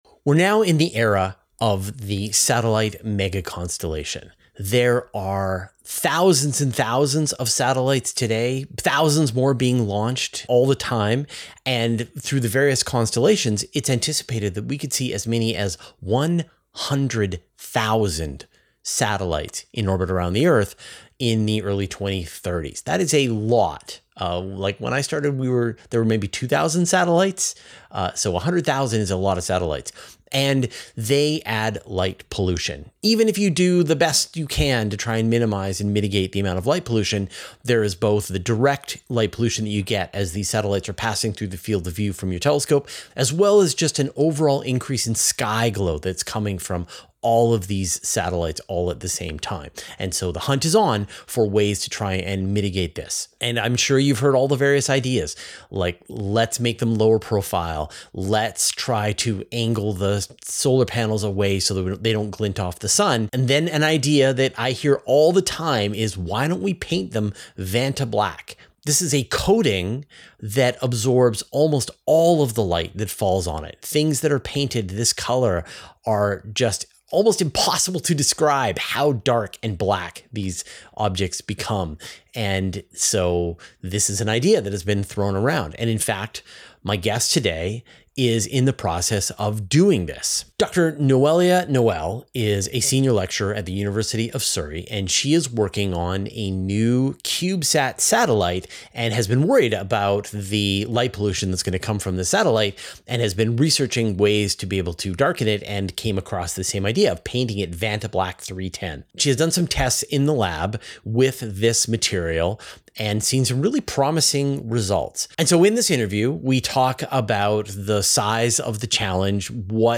[Interview] Fighting Light Pollution from Satellites with Ultra Black Paint